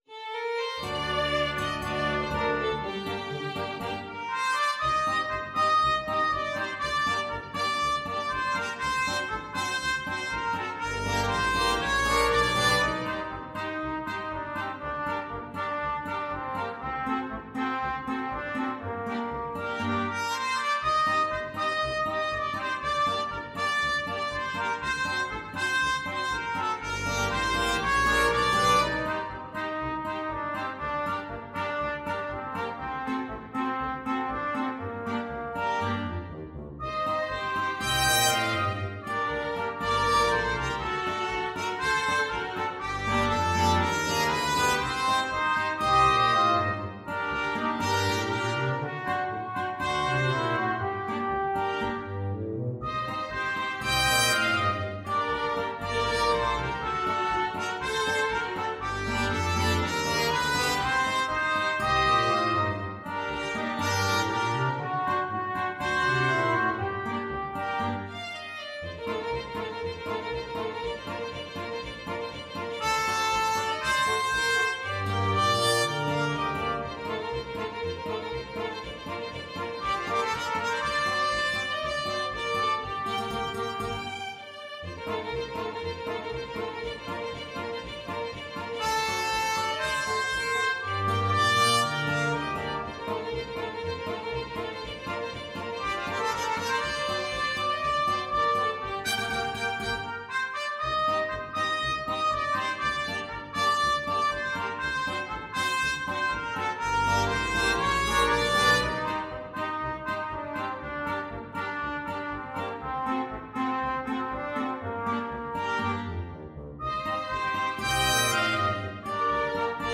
Free Sheet music for Flexible Mixed Ensemble - 5 Players
Trumpet
Violin
Guitar (Chords)
Trombone
Tuba
2/2 (View more 2/2 Music)
Fast Two in a Bar =c.120
Bb major (Sounding Pitch) (View more Bb major Music for Flexible Mixed Ensemble - 5 Players )